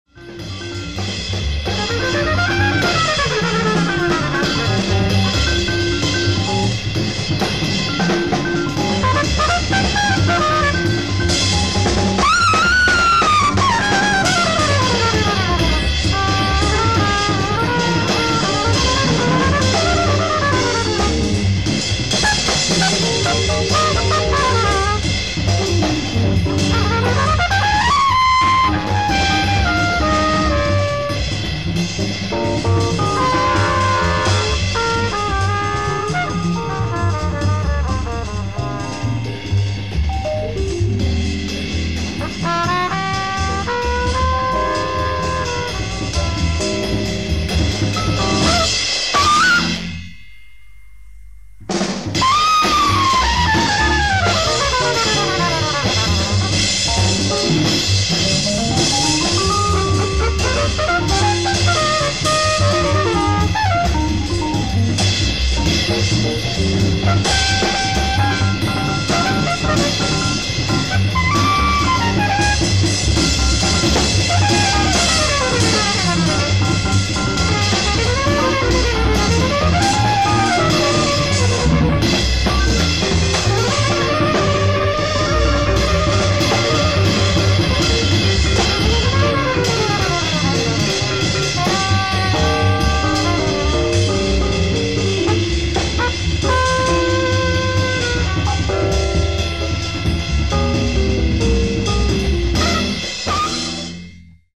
Live At Theatro Sistina, Roma, Italy 10/27/1969
SOUNDBOARD RECORDING